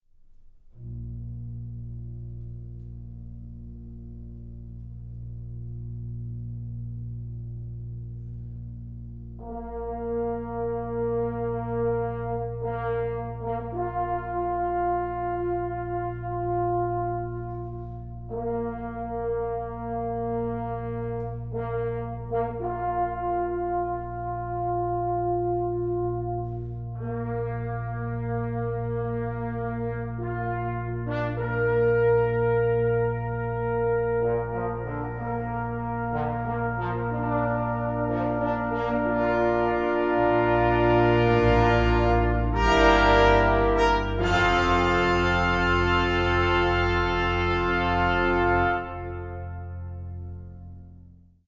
Stereo
trumpet
French horn
bass trombone